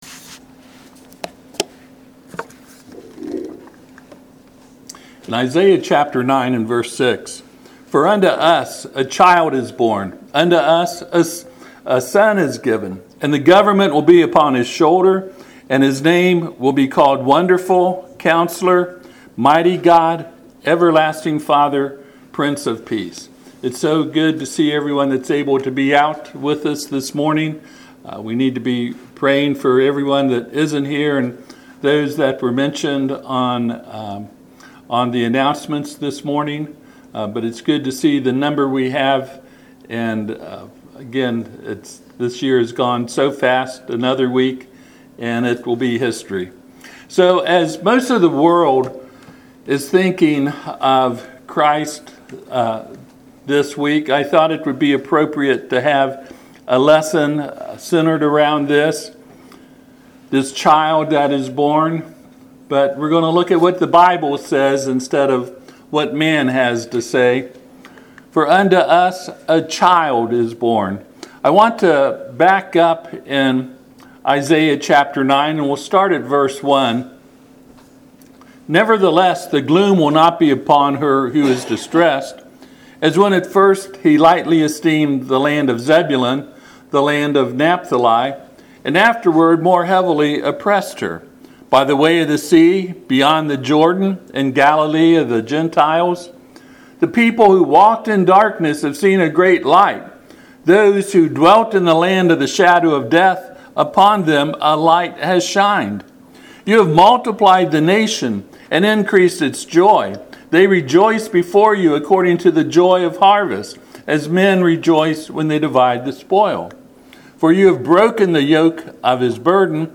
Passage: Isaiah 9:6-7 Service Type: Sunday AM